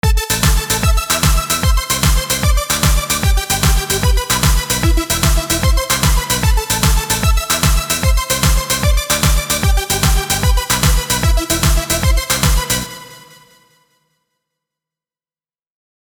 I recommend taking the 16 kHz and up range down about 2 dBs to smooth everything out.
It involves a lot of duplication and slight variation in saw waveforms to get a lush evolving sound.